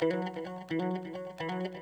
guitar01.wav